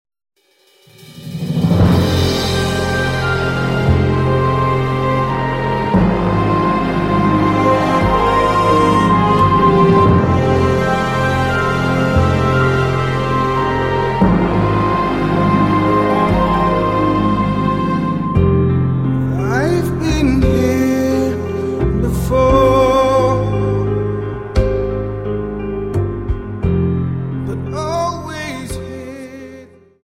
Dance: Slow Waltz 29